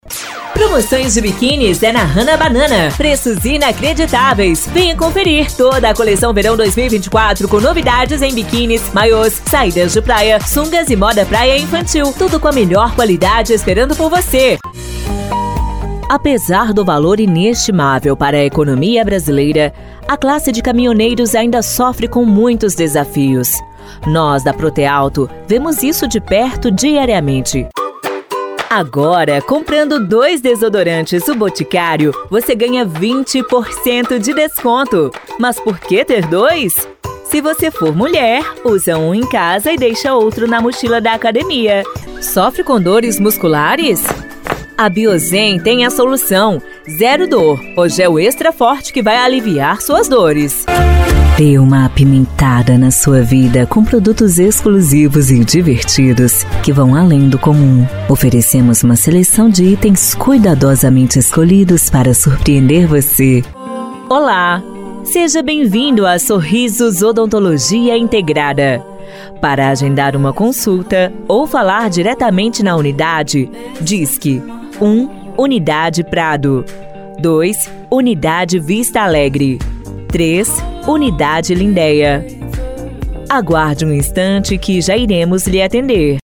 Spot Comercial
Vinhetas
Padrão
Impacto
Animada